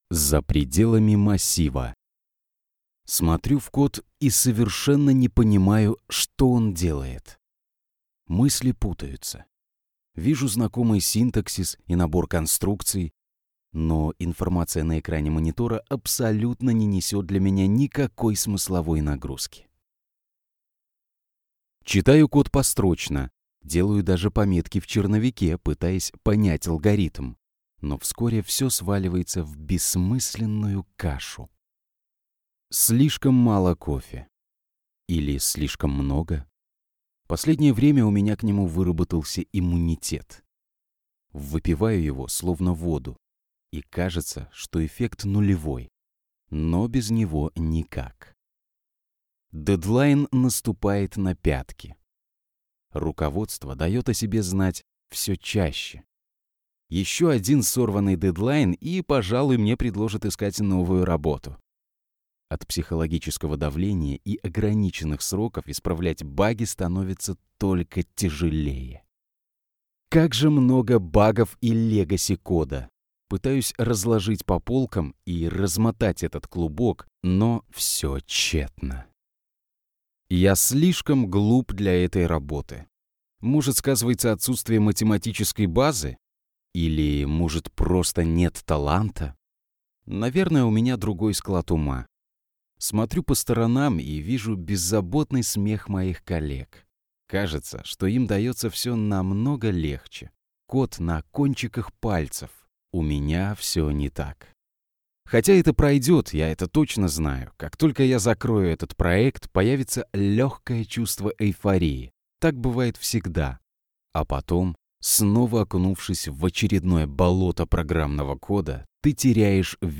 Aудиокнига За пределами массива